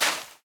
PixelPerfectionCE/assets/minecraft/sounds/step/sand4.ogg at mc116
sand4.ogg